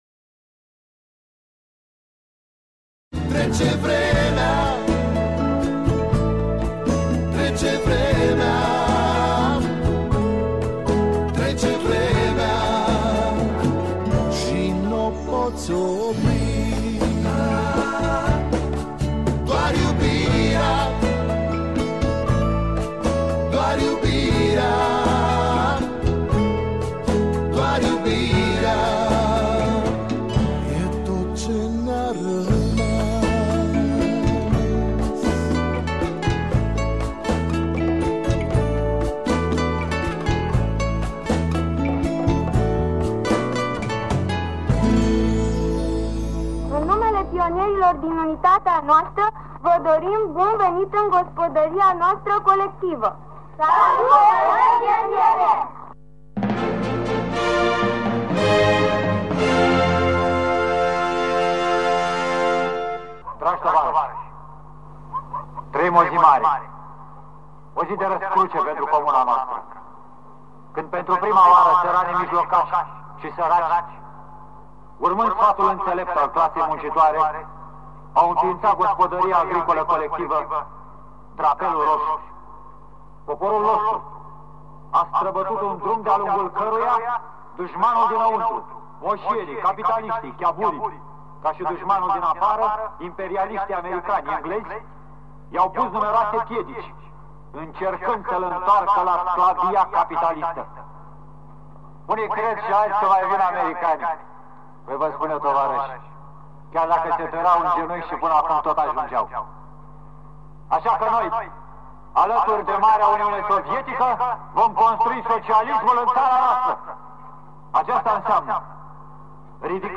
Feature